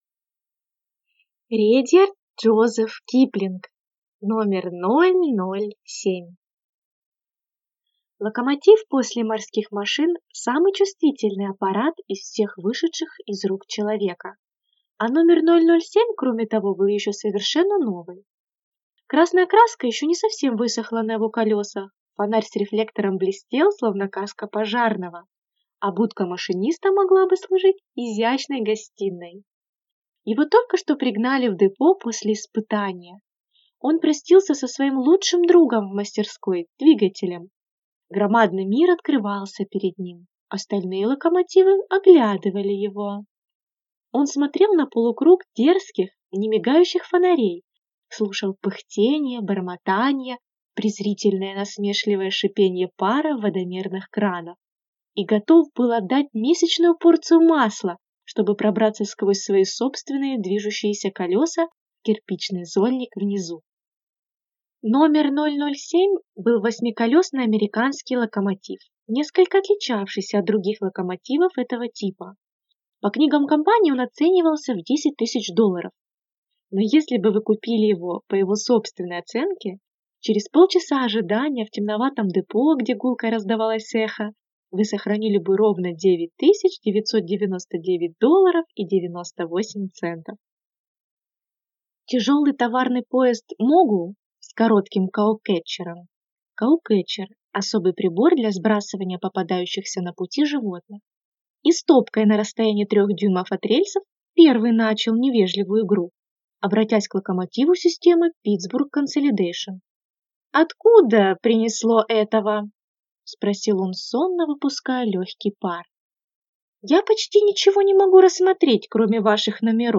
Aудиокнига №007 Автор Редьярд Джозеф Киплинг